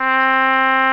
English Horn Sound Effect
english-horn.mp3